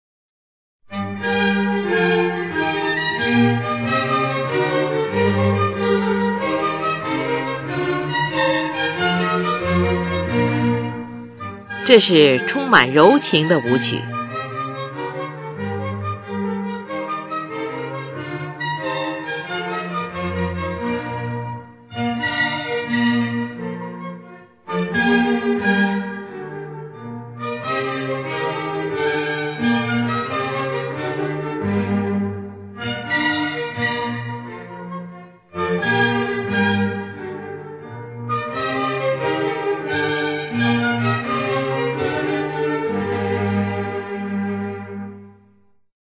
in F Major
是一部管弦乐组曲。
乐器使用了小提琴、低音提琴、日耳曼横笛、法兰西横笛、双簧管、圆号、小号等